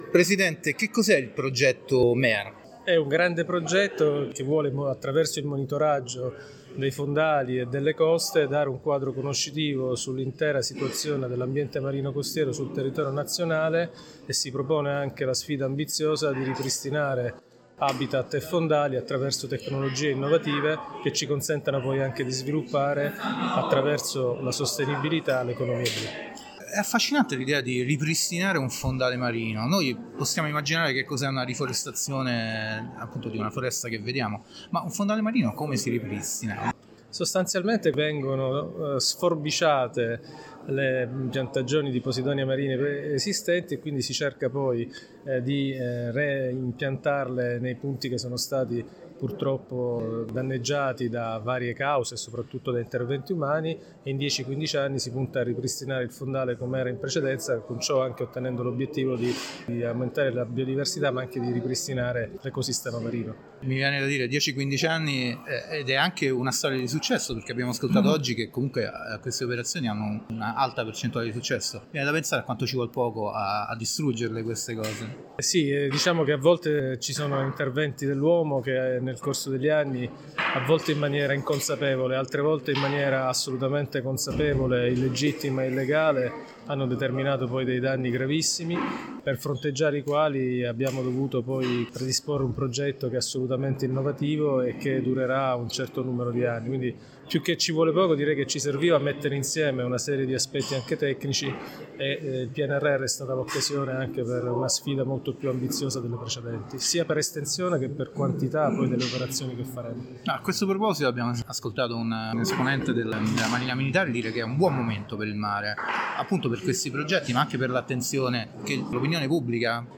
Interviste Pianeta
In sede di presentazione ne abbiamo parlato con Stefano Laporta, presidente dell’ISPRA.